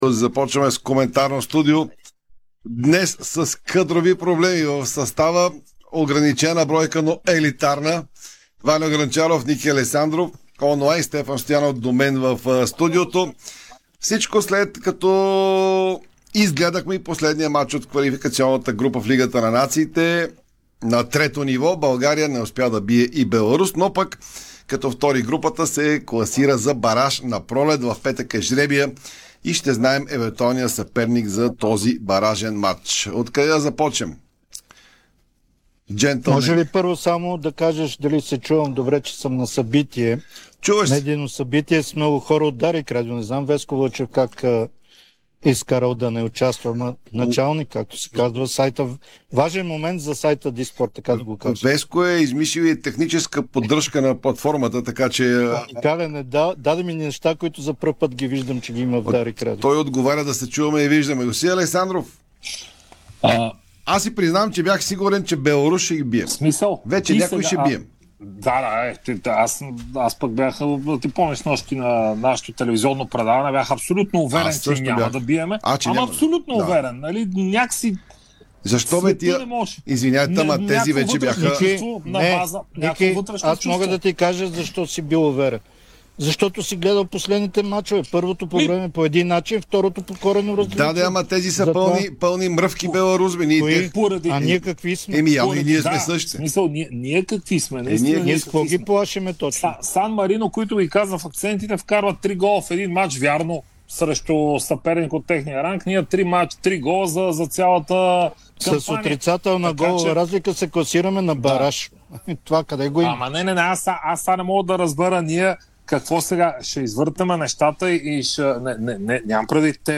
Коментарно студио
home-dsport-public_html-var-upload-1119komentarnostudio.mp3